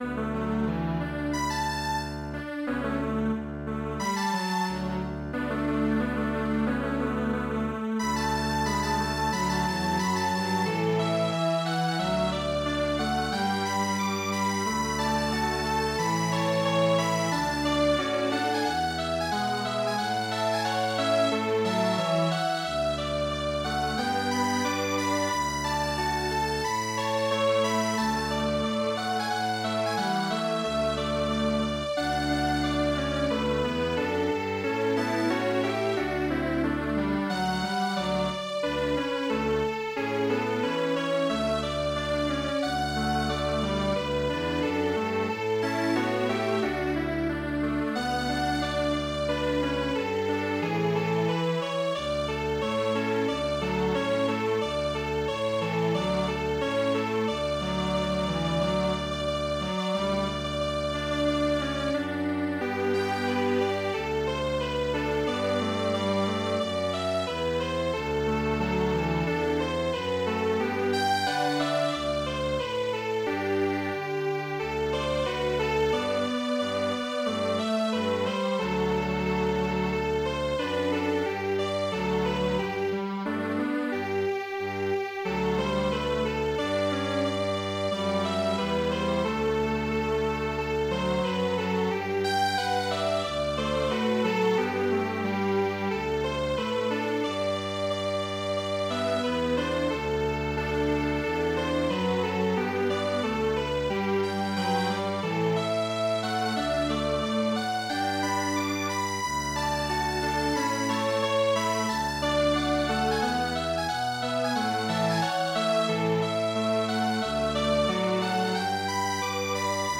弦楽四重奏